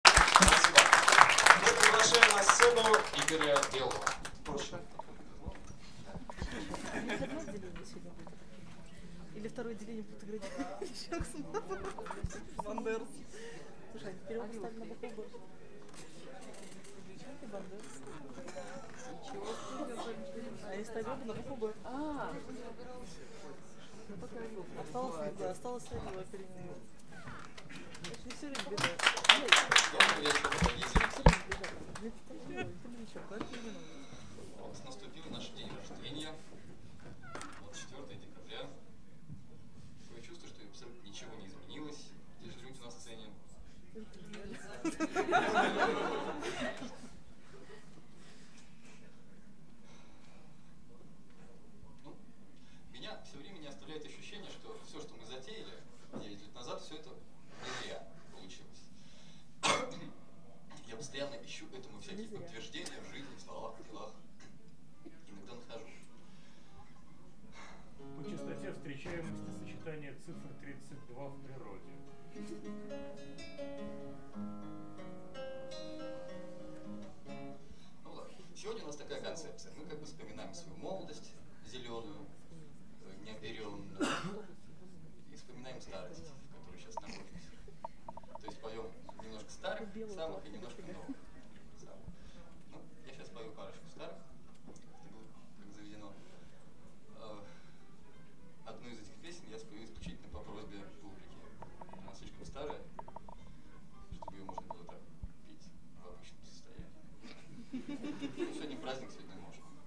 Запись Дня Рождения "32-го Августа" в "Перекрёстке" 24.12.2003